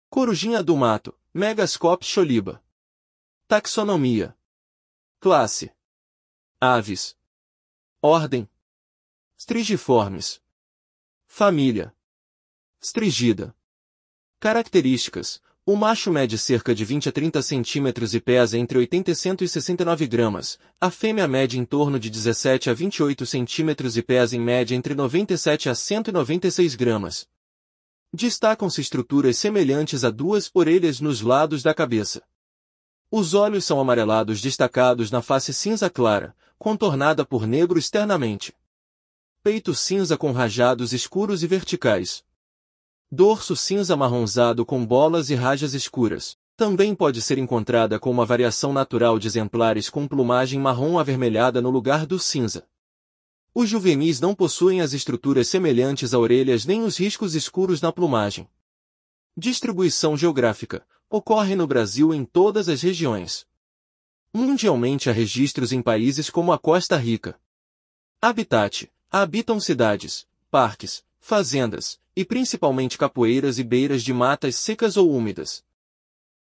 Corujinha-do-mato (Megascops choliba)